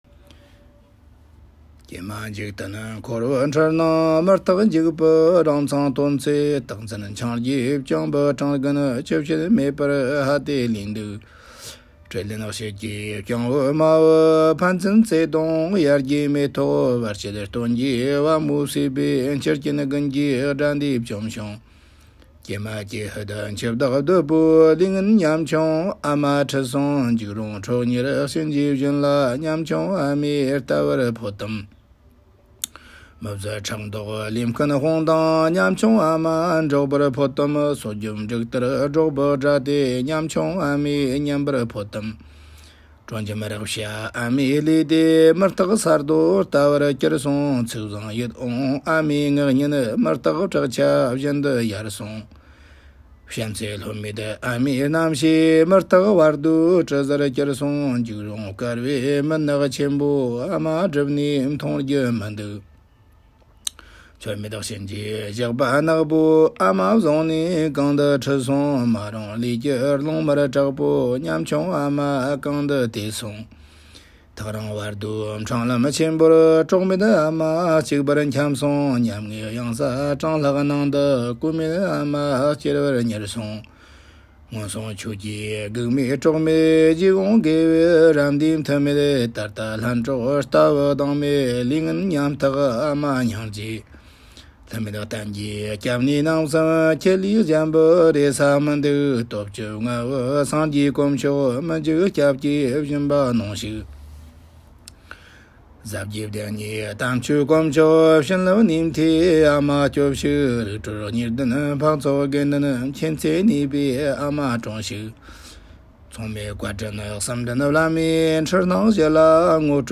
菩提万事---母亲节 大恩上师唱诵忆念母恩的道歌